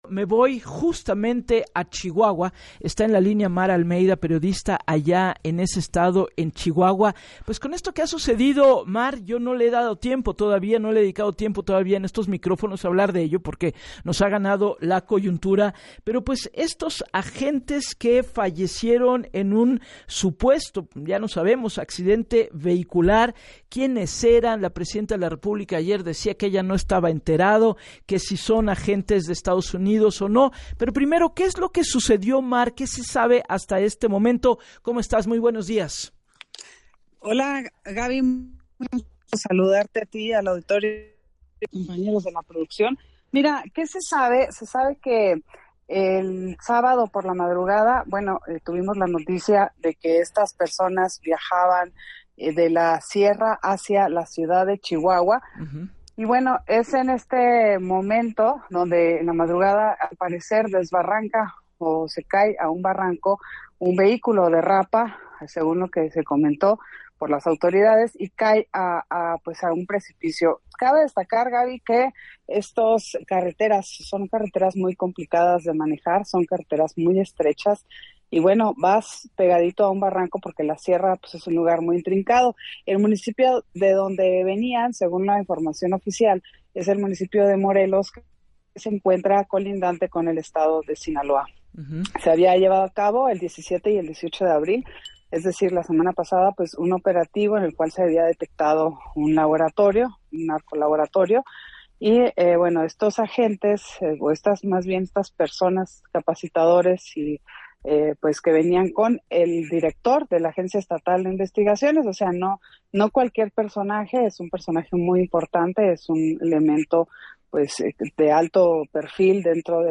Periodista en Chihuahua